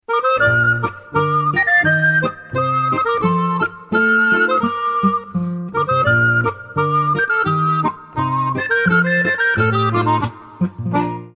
Volkstänze aus Niederösterreich